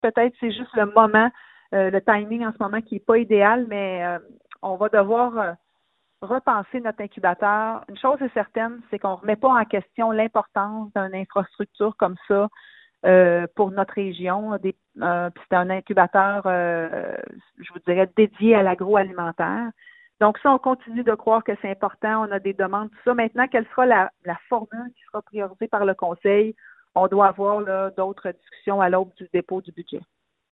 La mairesse Geneviève Dubois croit encore en cette formule, mais pense que le contexte économique a sans doute découragé de potentiels investisseurs :